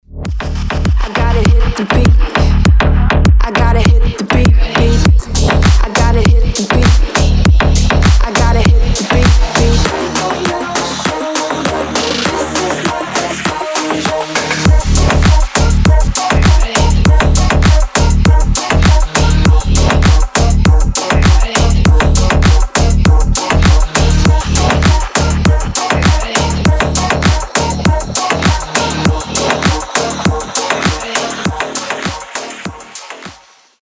• Качество: 320, Stereo
EDM
мощные басы
Bass House
качающие
G-House
Крутой размеренный клубняо